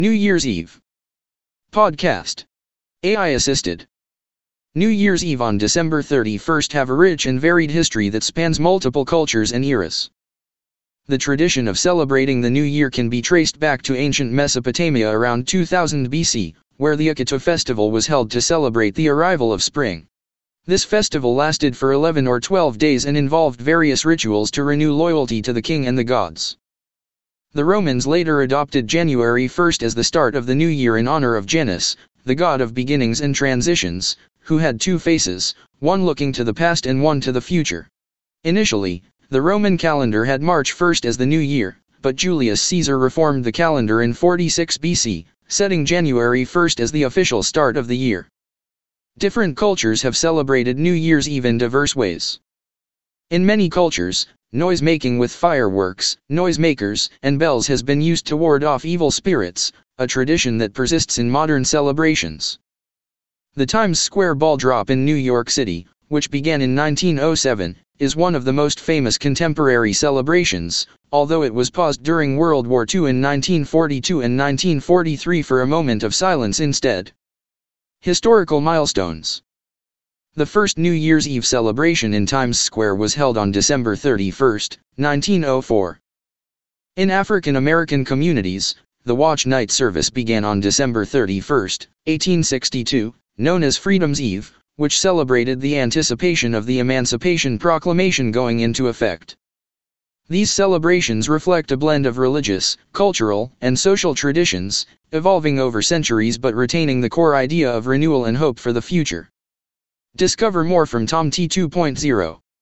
PODCAST. AI assisted.